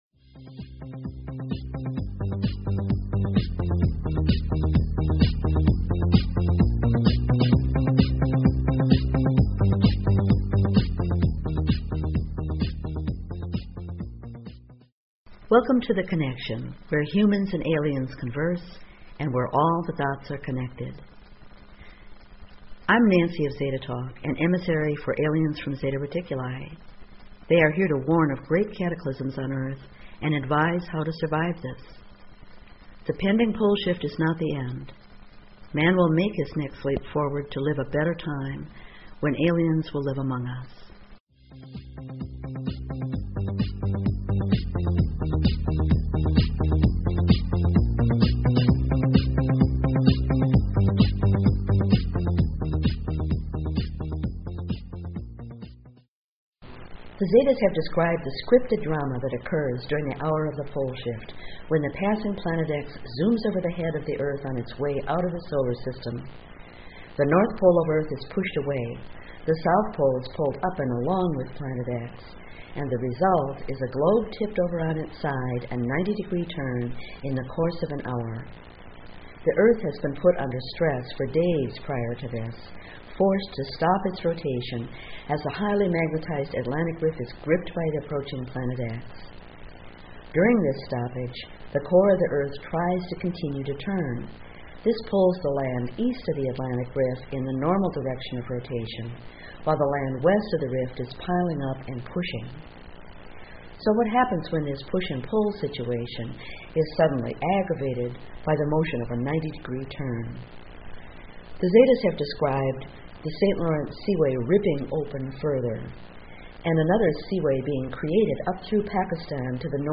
Courtesy of BBS Radio